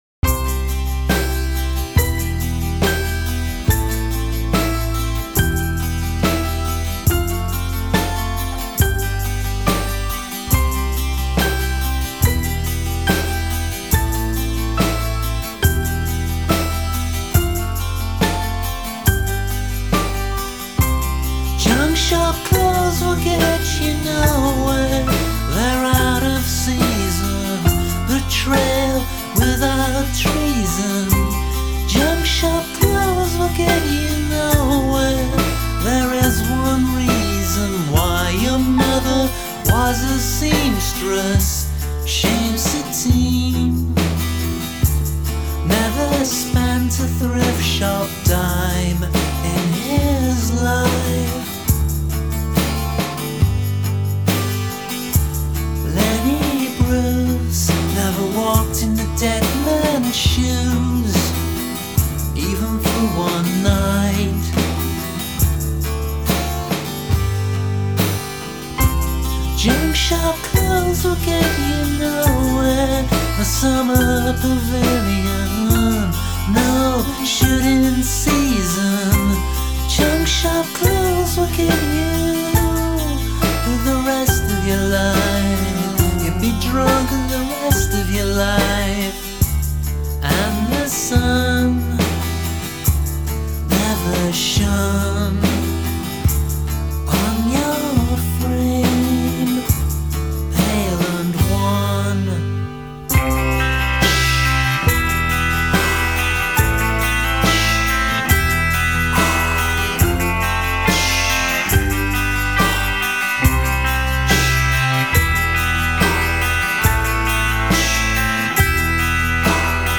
Жанр: alternative rock, indie pop